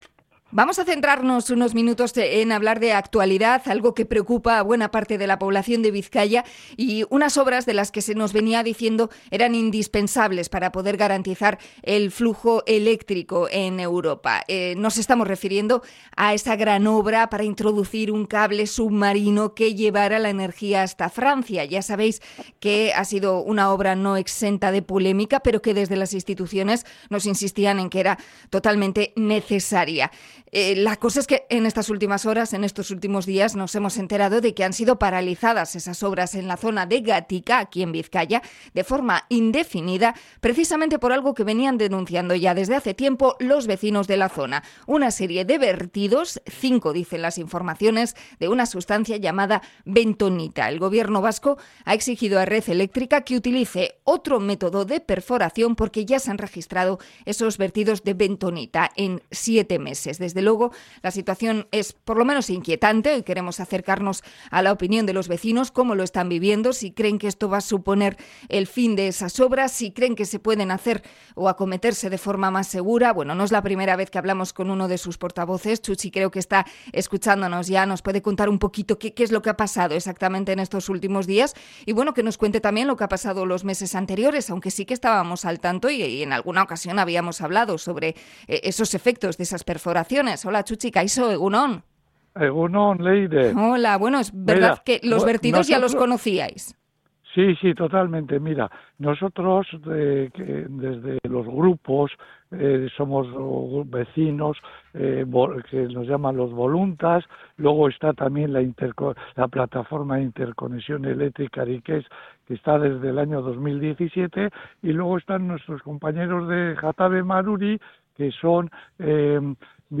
Entrevista a los vecinos de Gatika por la paralización de la interconexión eléctrica